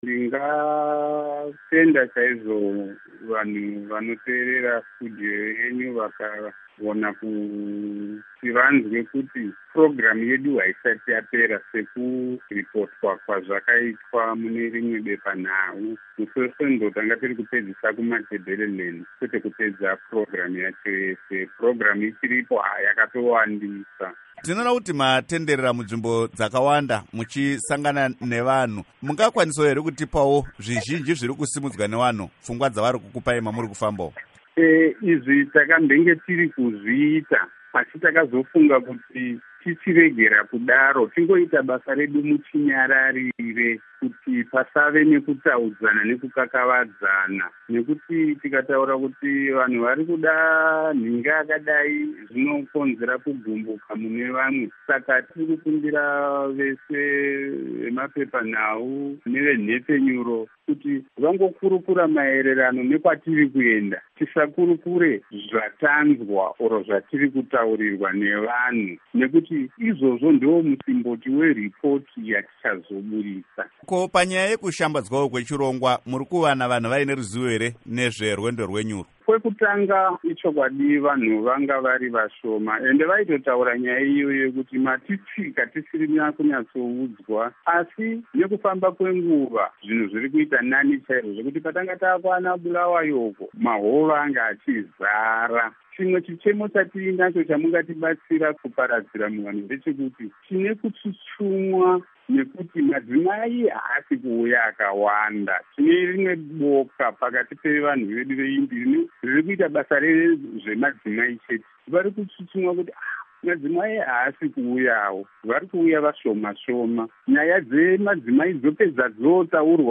Hurukuro naVaGeoff Nyarota